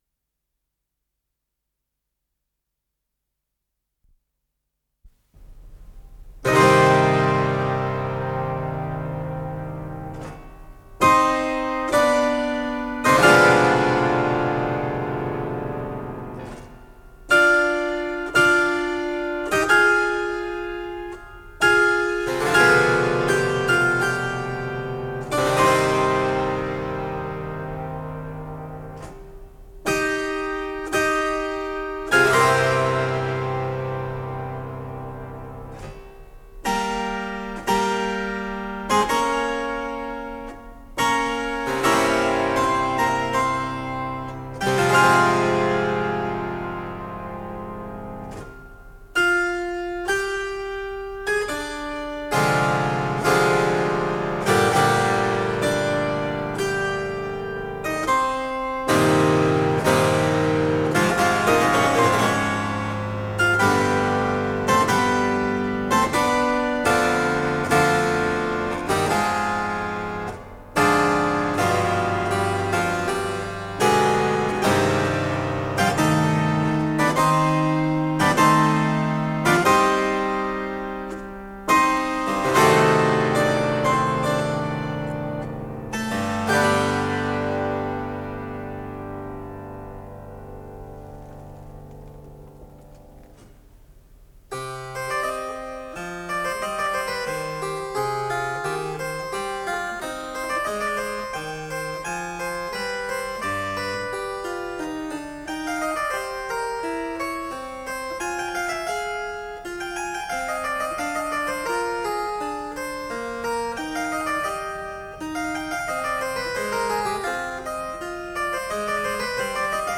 с профессиональной магнитной ленты
Симфония
ИсполнителиРальф Киркпатрик - клавесин